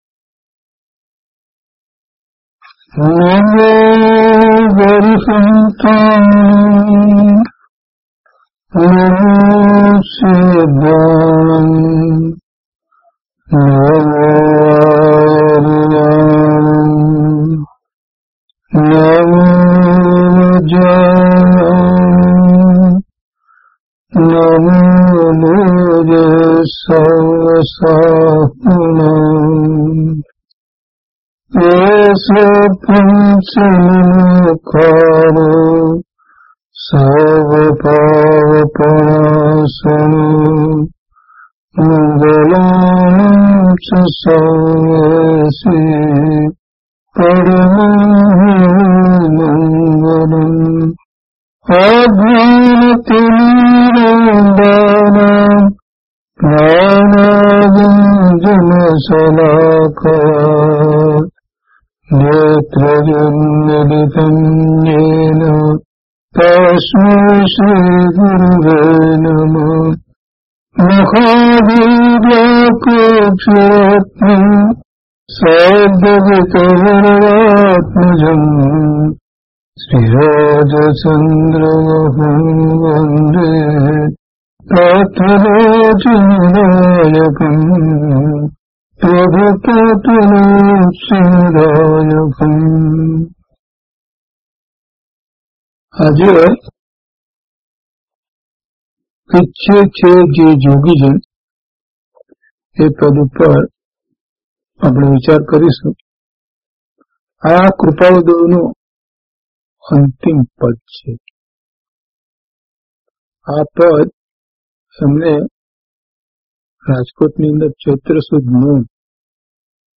DHP075 Ichchhe Chhe Je Jogijan  - Pravachan.mp3